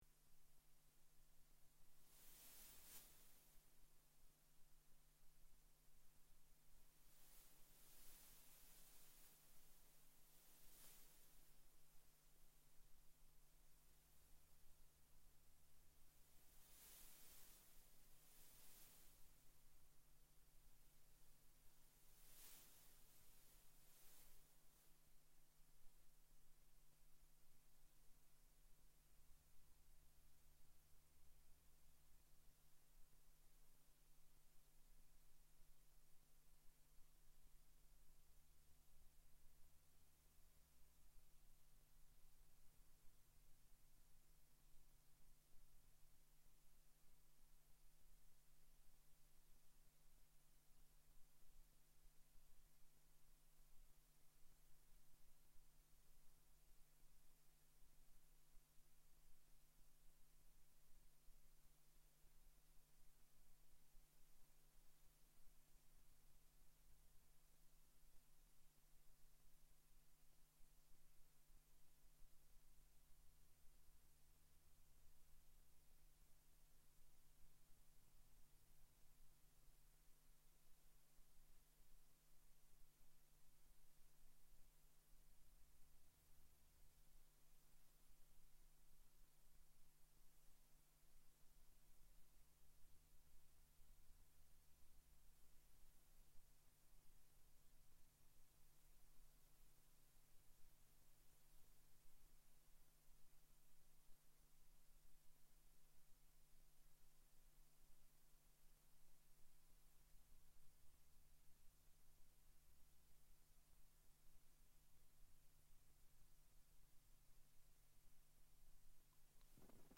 Lecture 19.9.2016 17f700edcc8a4de3add445d9da0405c2
Android iPhone/iPad First two minutes of video is without audio. Audio begins at 00:02:12.